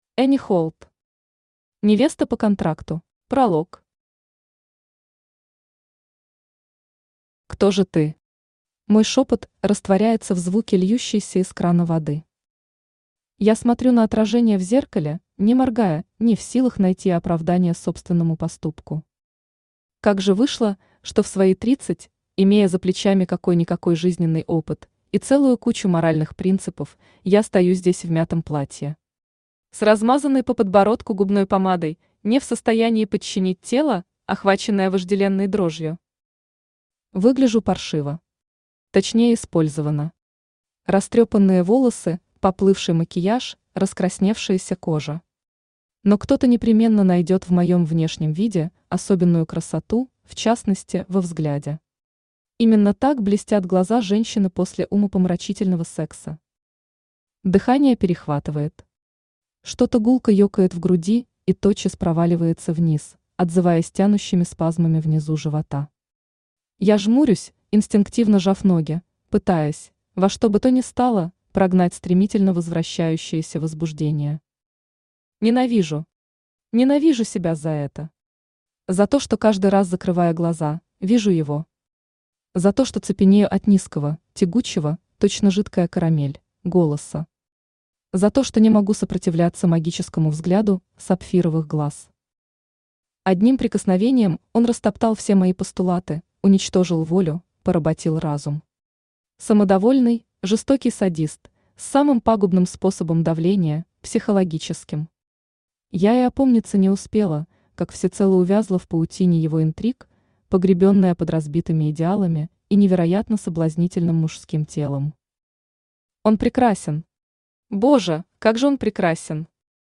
Аудиокнига Невеста по контракту | Библиотека аудиокниг
Aудиокнига Невеста по контракту Автор Энни Холт Читает аудиокнигу Авточтец ЛитРес.